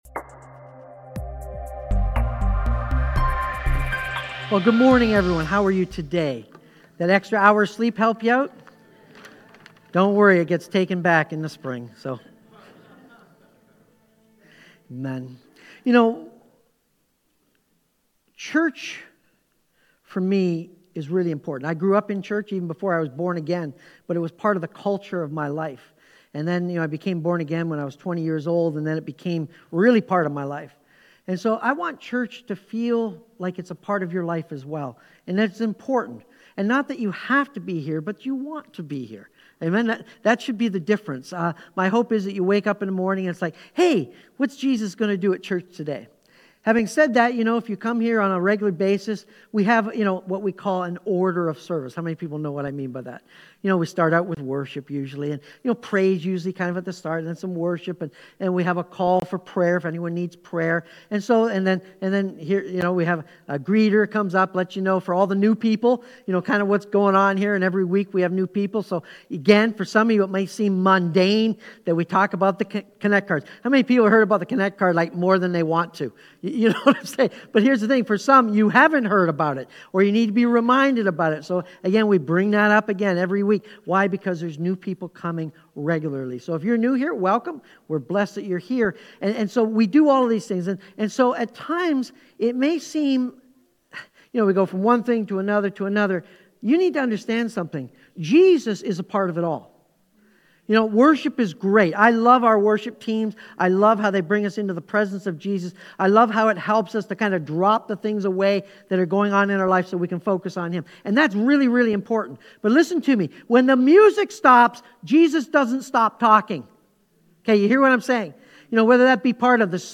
Sermon-On-The-Mount-Its-All-About-The-Extra-Mile.mp3